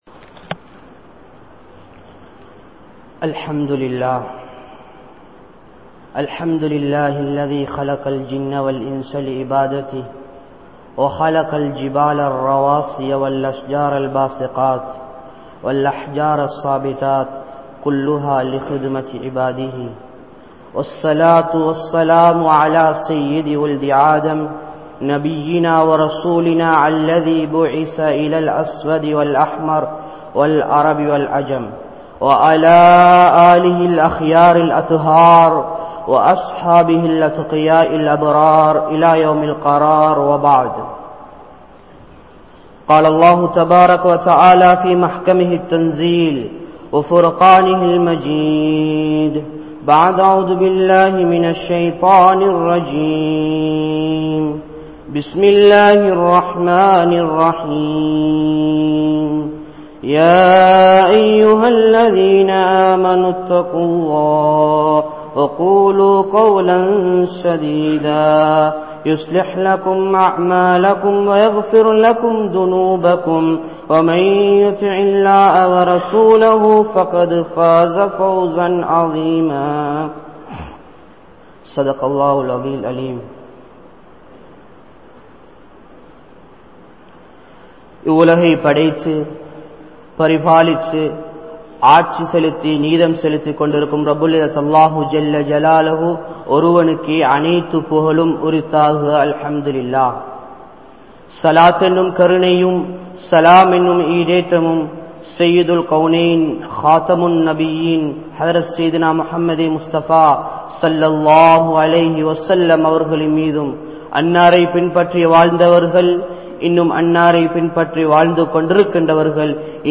Thaqwa | Audio Bayans | All Ceylon Muslim Youth Community | Addalaichenai
Gorakana Jumuah Masjith